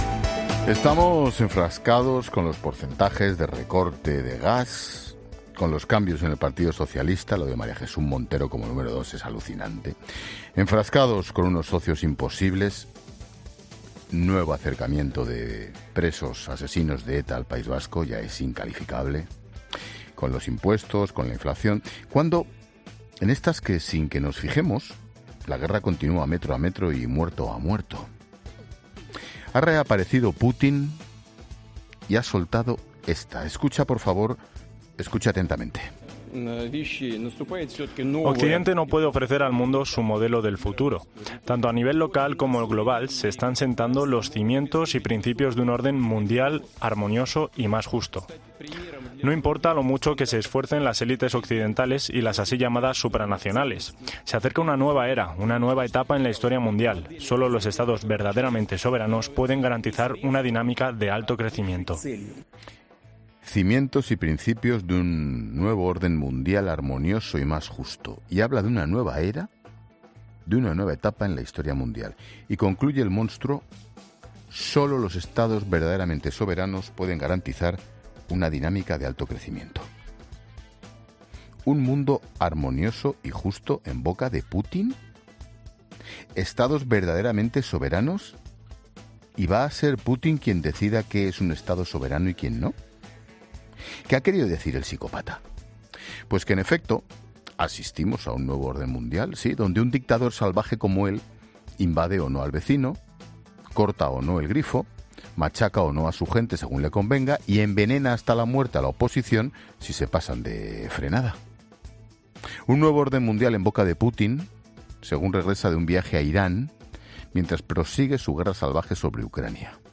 Monólogo de Expósito